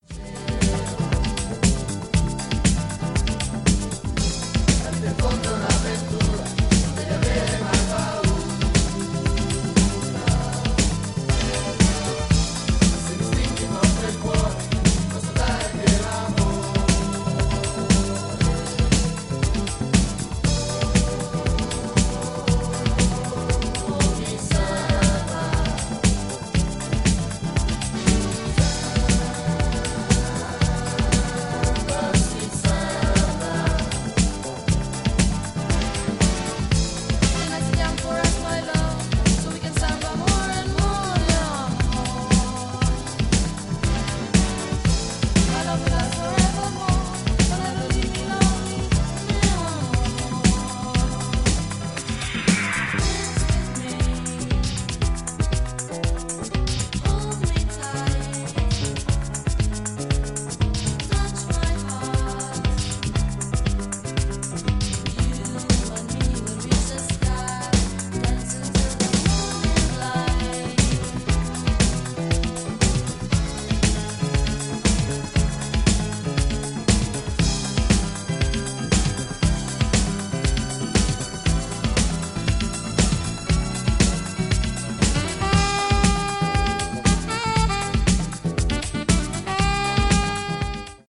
ボッサやフュージョン感を持ったバレアリック・ブギーを長めの尺で展開していくA面
ジャンル(スタイル) DISCO / NU DISCO / RE-EDIT